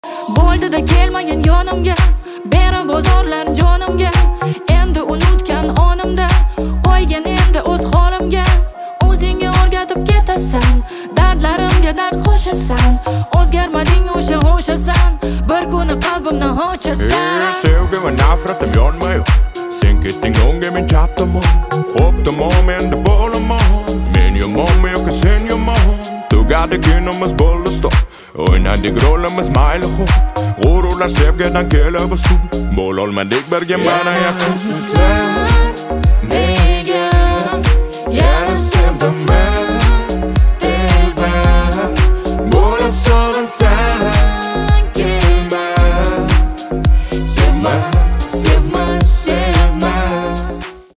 Казахские песни